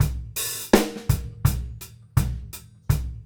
GROOVE 160FR.wav